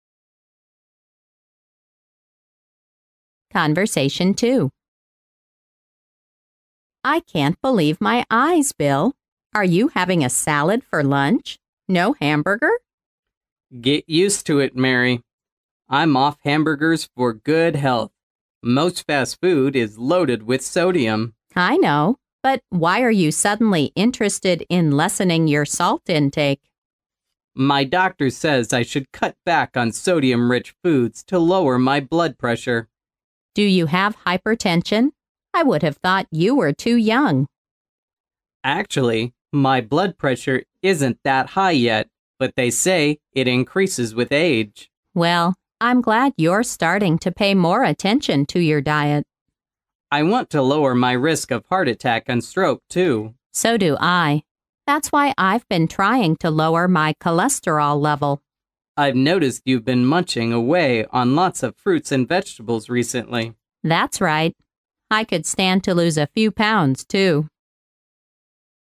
潮流英语情景对话张口就来Unit20：高血压mp3